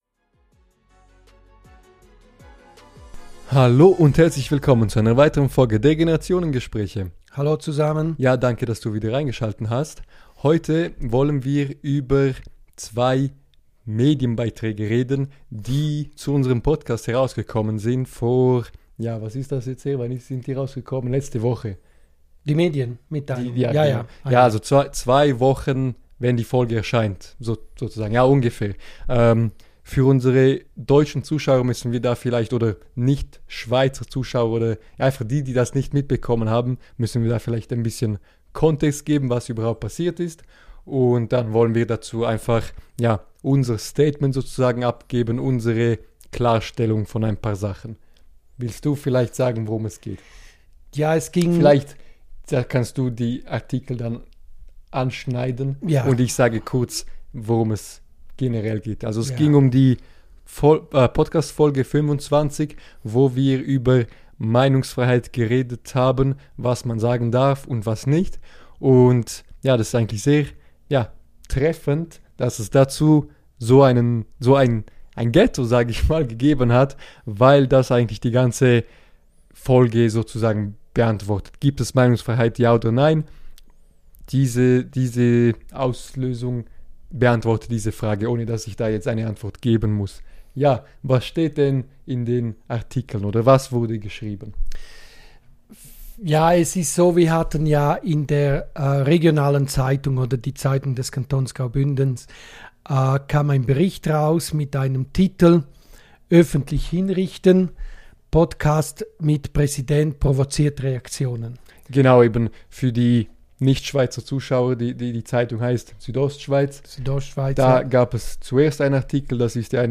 Außerdem diskutieren wir grundsätzlicher, wie Medienlogik funktioniert, warum Zuspitzung oft wichtiger ist als Inhalt und wie man damit umgeht, wenn man plötzlich selbst Teil einer Schlagzeile wird. Eine ruhige, ehrliche Folge über Einordnung, Verantwortung und den Umgang mit Öffentlichkeit.